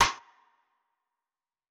Huncho Perc.wav